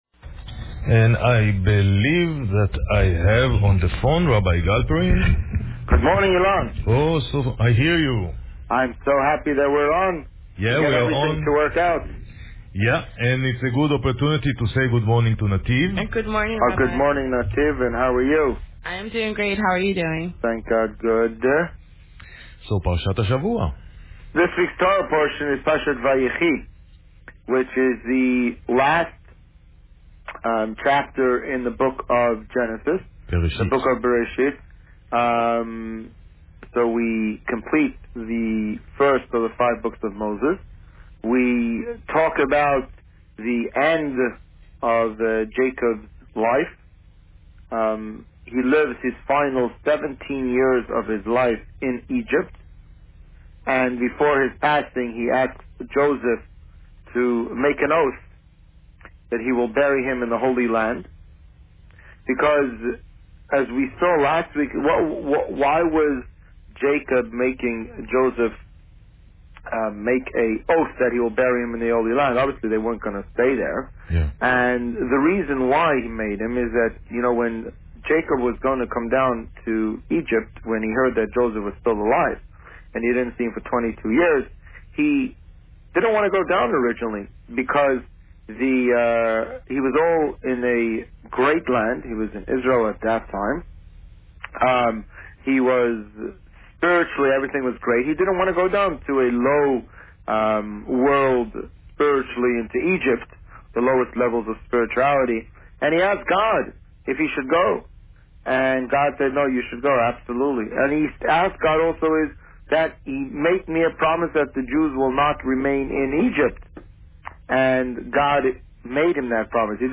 This week, the Rabbi spoke about Parsha Vayechi. Listen to the interview here.